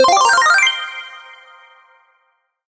quest_appear_01.ogg